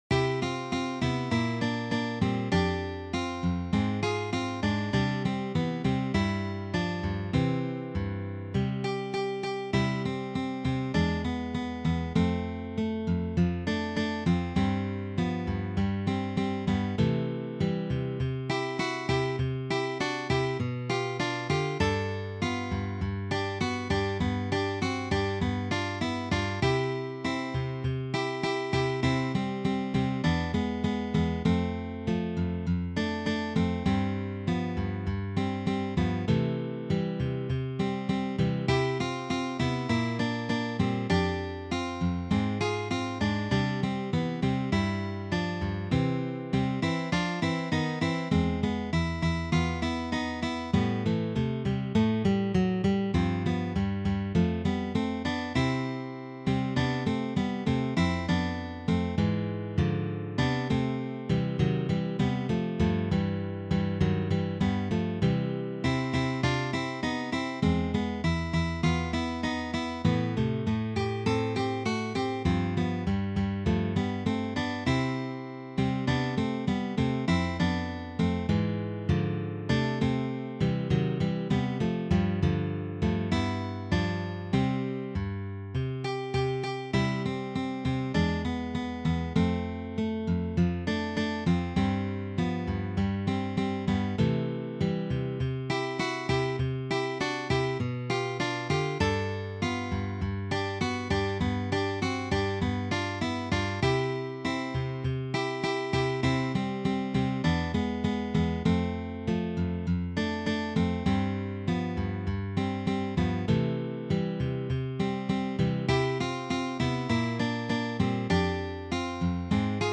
for three guitars
This is written in the Latin-Tango style.